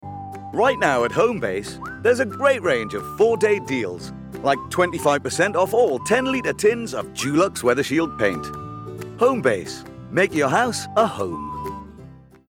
Friendly, conversational with gravitas.
• Male
• Newcastle (Geordie)